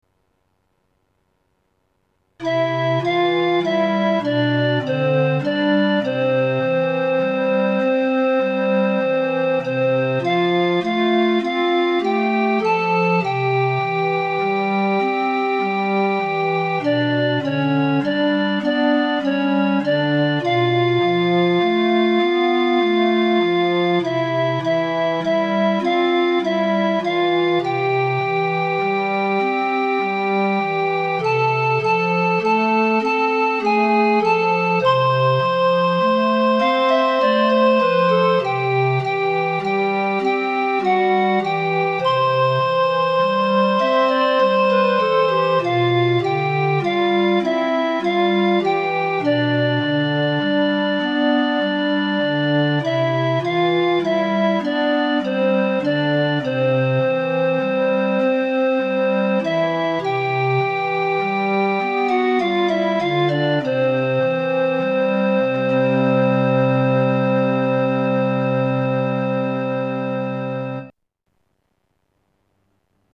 ストリート・オルガン